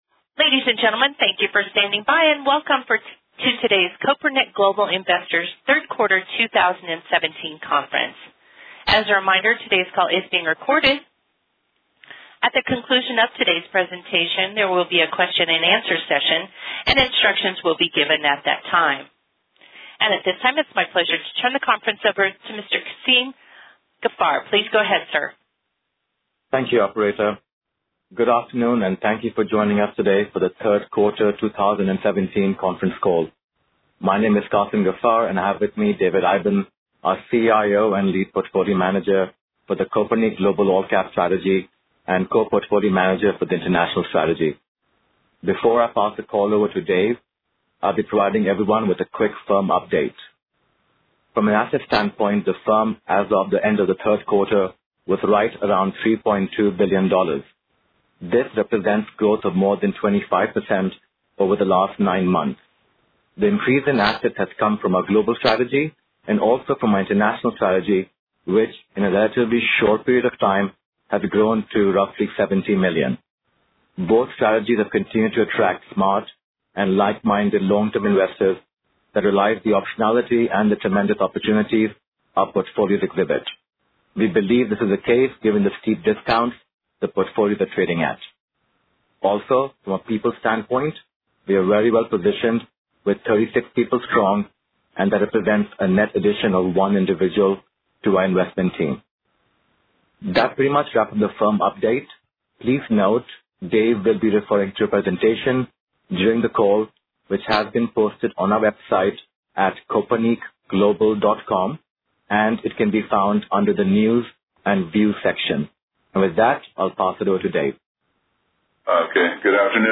Q3 2017 Conference Call - Kopernik Global Investors
Kopernik-Global-3Q-Conference-Call-2017.mp3